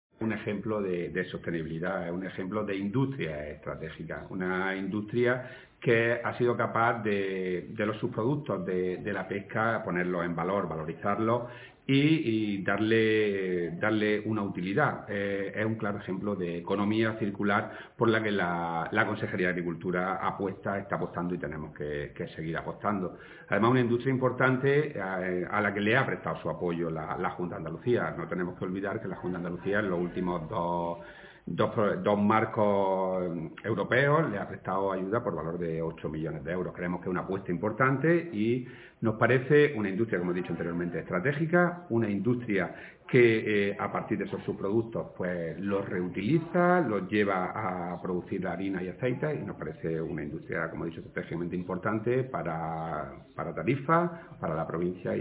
Declaraciones consejero Harinas de Andalucía